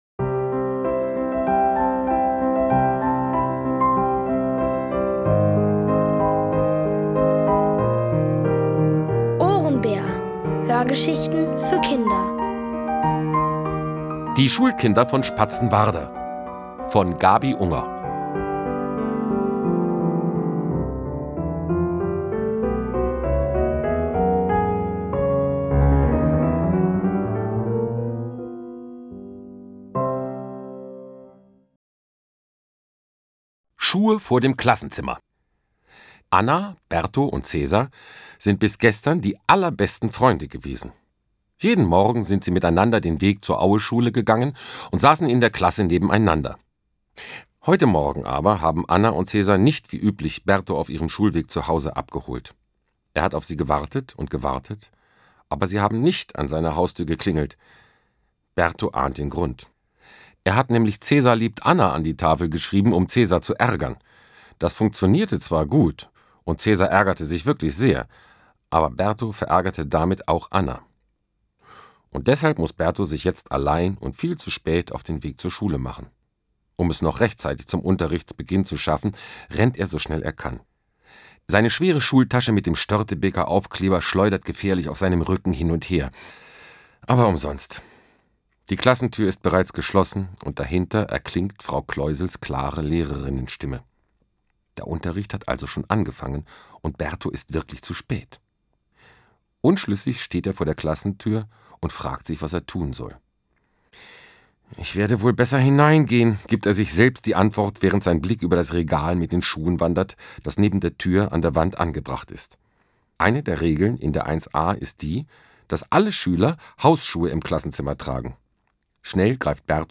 Hörgeschichten empfohlen ab 6: